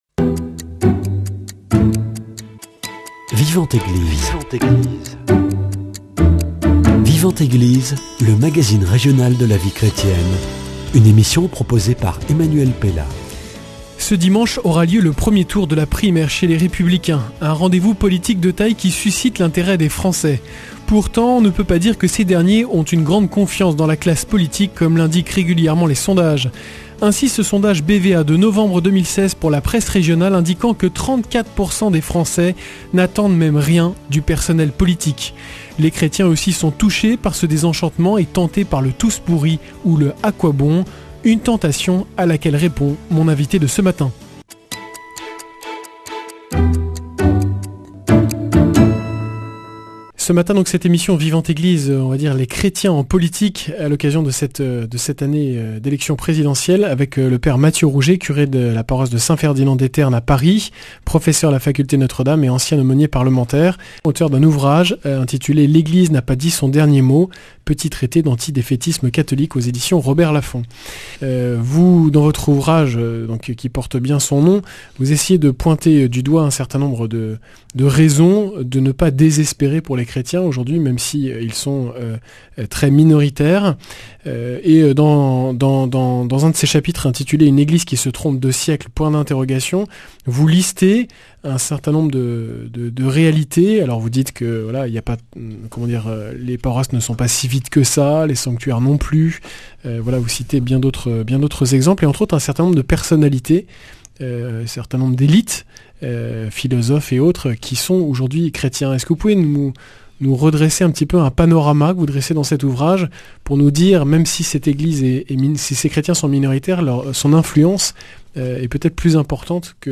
Speech